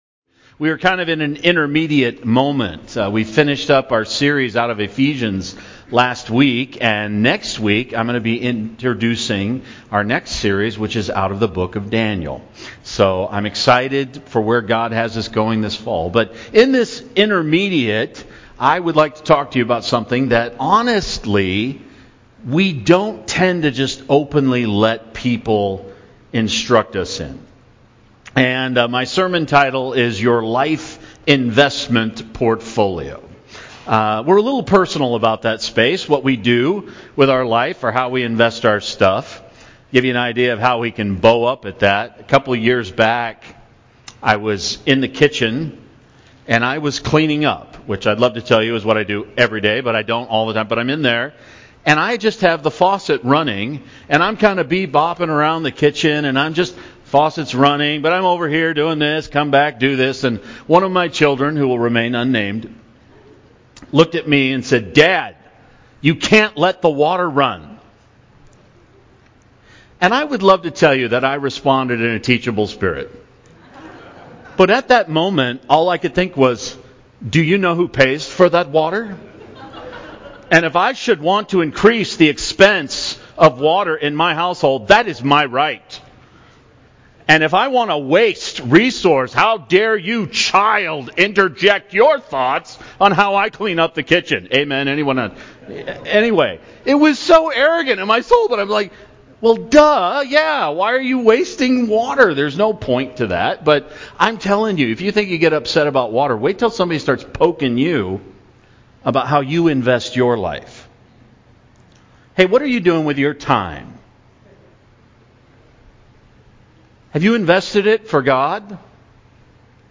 Passage: Ephesians 6:7-10 Service Type: Sunday morning